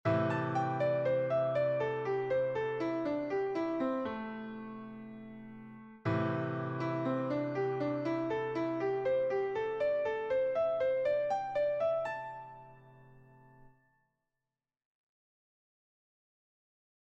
Improvisation Piano Jazz
Comme les gammes pentatoniques majeures et mineures comportent les mêmes notes, le lick ci dessous est valable pour les 2 gammes :